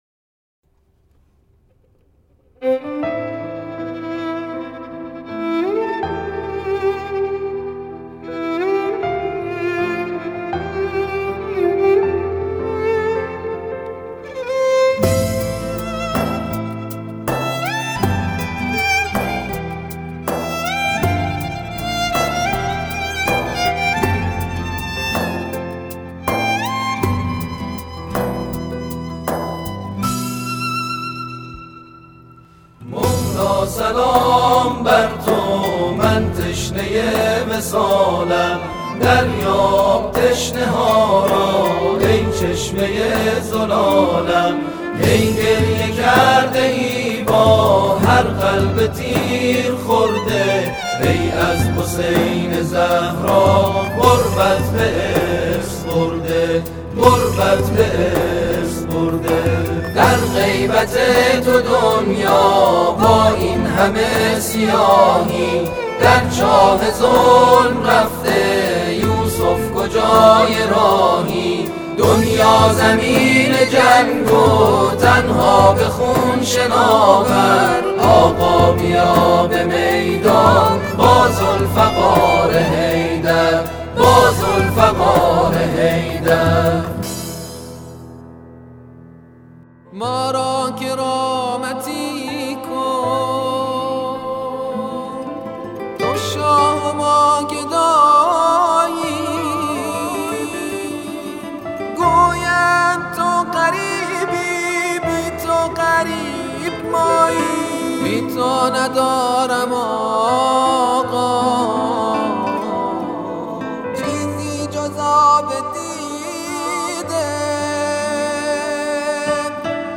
Teqlər: iqna ، imam mehdi ، İmamət bayramı ، dini nəğmə